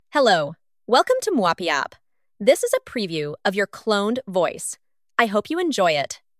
Minimax Voice Clone creates a high-fidelity digital clone of a speaker’s voice from a short reference audio sample. It reproduces the speaker’s tone, emotion, accent, rhythm, and speaking style, then generates new speech from any text input.
minimax-voice-clone.mp3